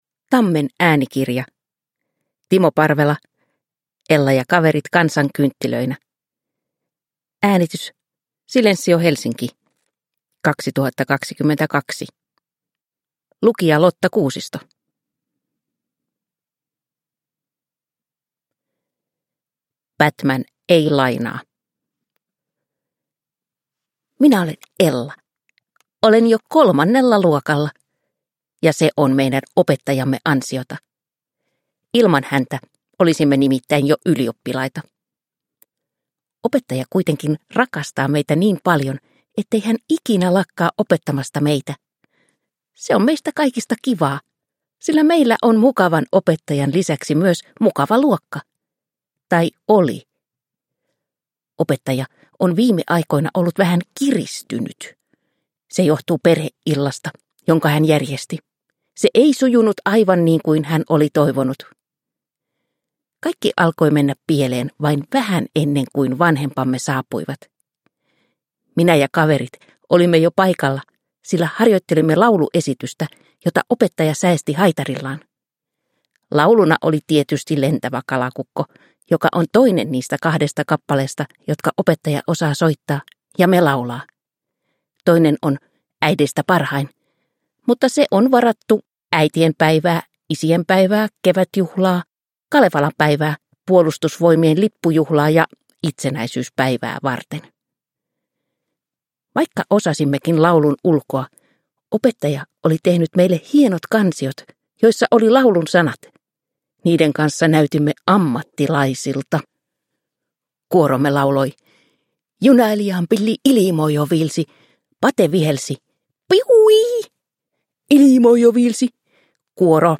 Ella ja kaverit kansankynttilöinä – Ljudbok – Laddas ner